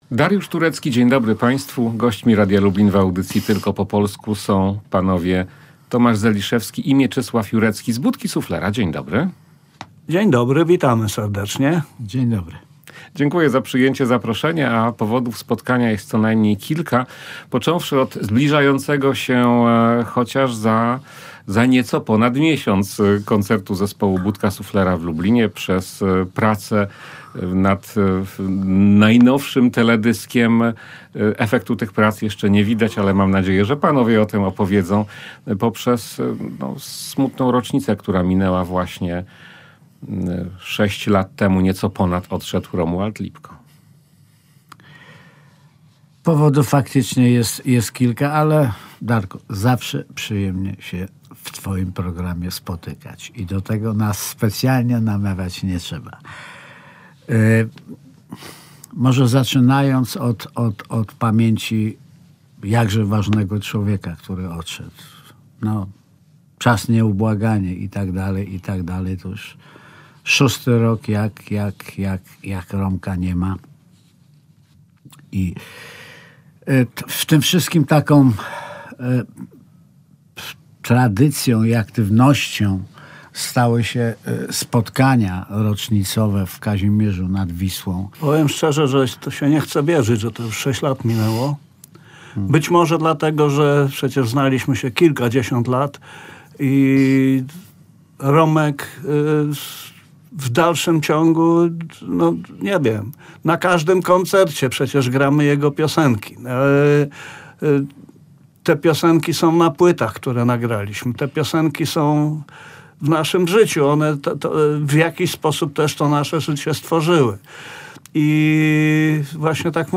Szósta rocznica śmierci Romualda Lipko, zbliżający się koncert w Lublinie, a także niedawna sesja filmowa do zapowiadanego nowego klipu Budki Suflera – to niektóre tematy rozmowy z muzykami zespołu Tomaszem Zeliszewskim i Mieczysławem Jureckim w audycji „Tylko po polsku”.